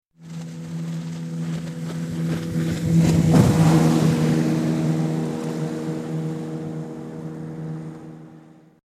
Vehicles
Boat(141K) -Helicopter(164K)